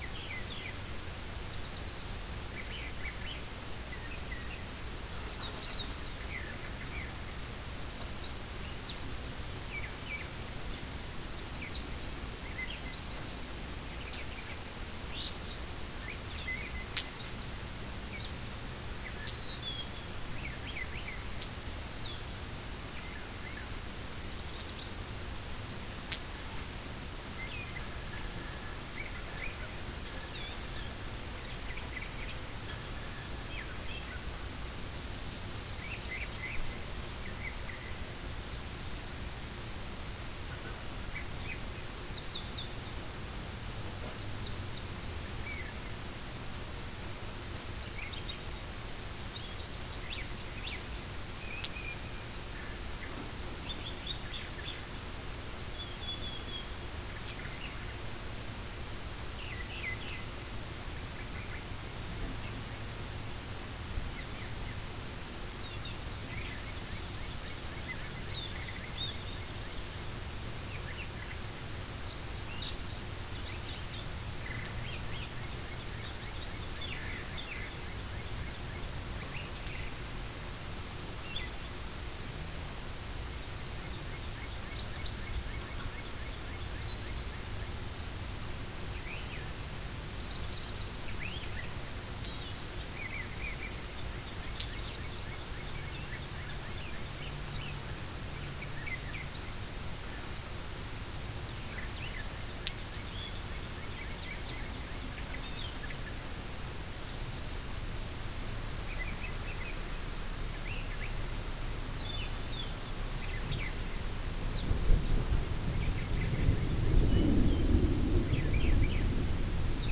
Lust auf 1 Stunde Regengeräusche zum Entspannen?
Um deinen Schlaf noch entspannter zu gestalten, haben wir ein kleines Geschenk für dich: eine 1-stündige MP3-Datei mit sanften Regengeräuschen von somnovia.
Einfach abspielen, zurücklehnen und die beruhigenden Tropfen genießen – so wird dein Schlafzimmer zur persönlichen Wohlfühloase.
Somnovia_Regen_ca1Std.mp3